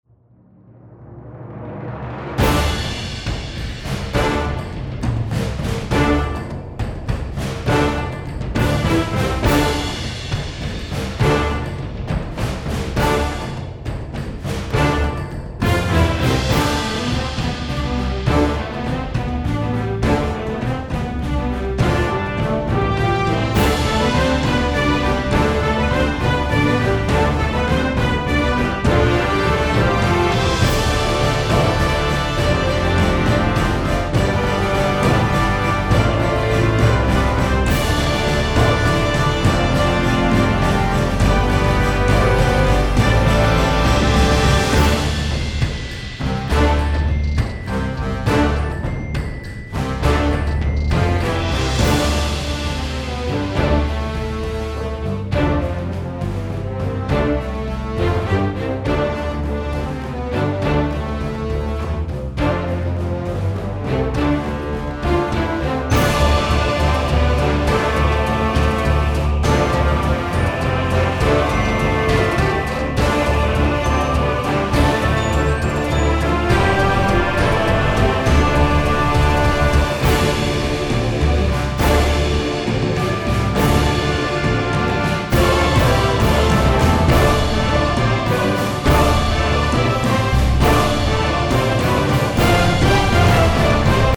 海賊が登場するシーンや海辺などの場面にぴったりです。